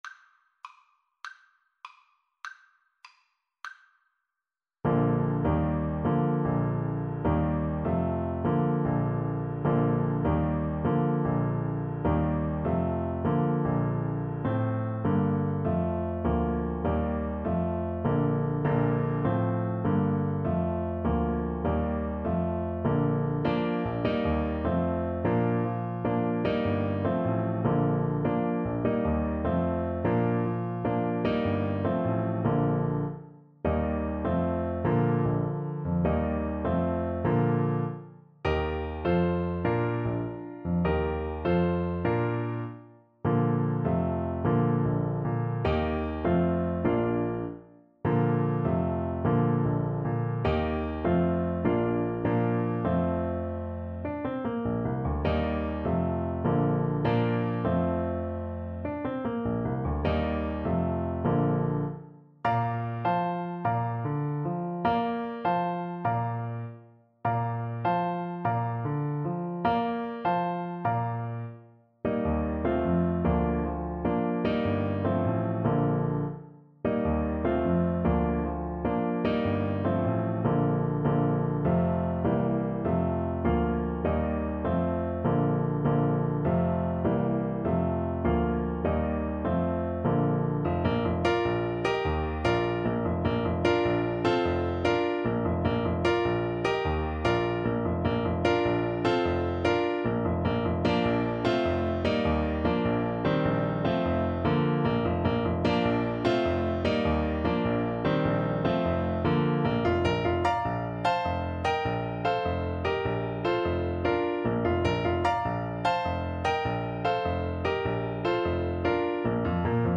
Play (or use space bar on your keyboard) Pause Music Playalong - Piano Accompaniment Playalong Band Accompaniment not yet available reset tempo print settings full screen
Bb major (Sounding Pitch) F major (French Horn in F) (View more Bb major Music for French Horn )
6/8 (View more 6/8 Music)
Classical (View more Classical French Horn Music)